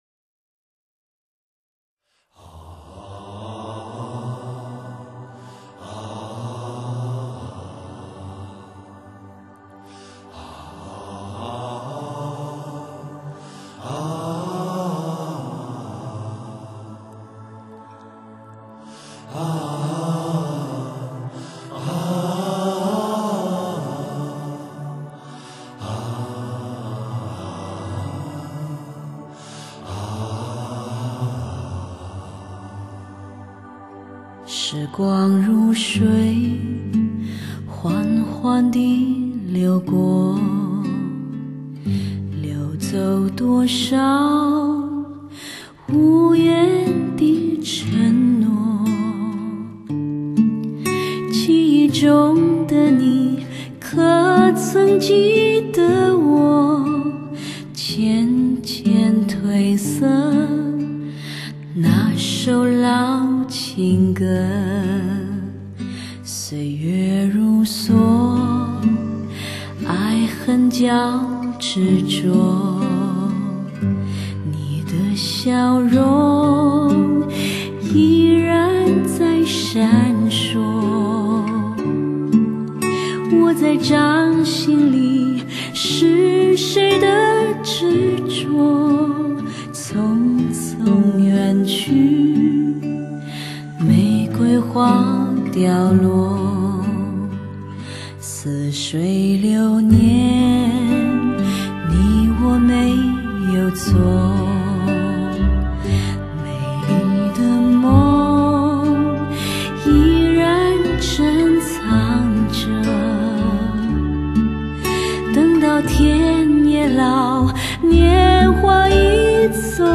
极品人声发烧天碟。
聆听这磁性的歌声，让思念与记忆蓦延……
无可比拟的磁性女声，体验极度Hi-Fi真谛。